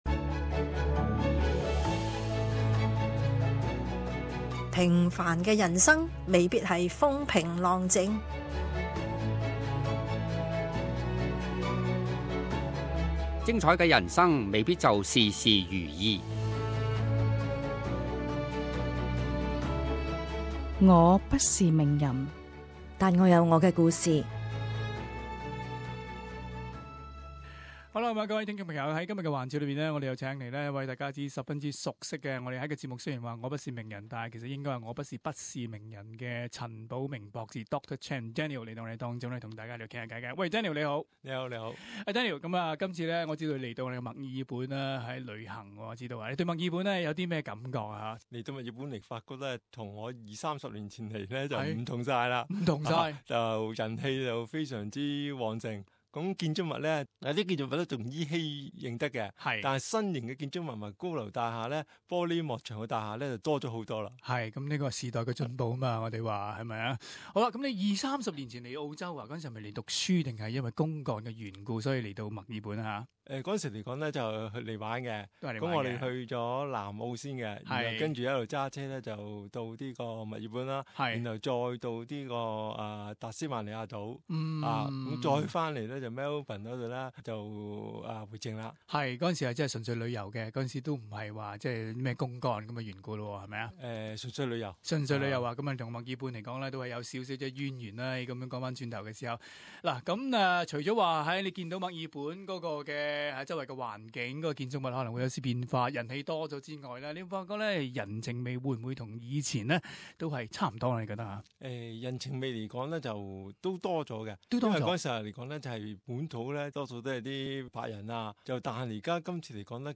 【我不是名人】访问寰宇金融节目嘉宾